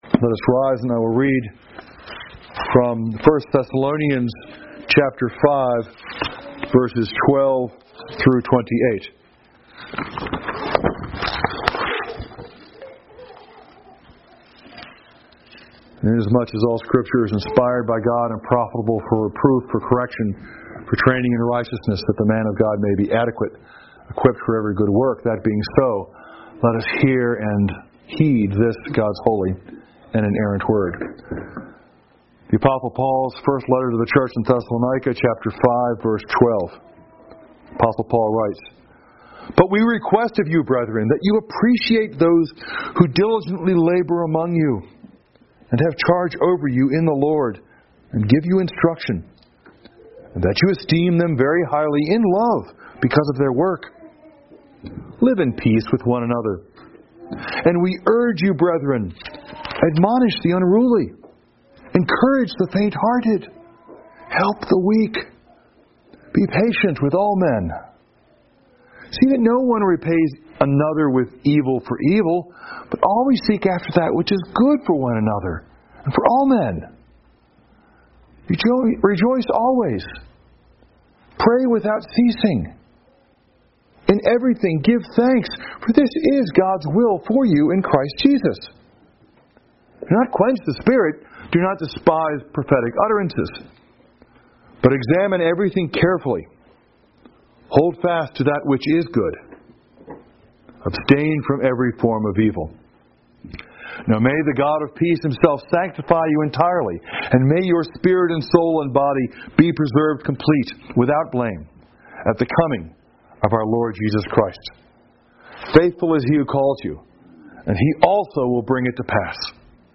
Series: Morning Worship
1 Thessalonians 5:12-28 Service Type: Sunday Morning %todo_render% « “Jesus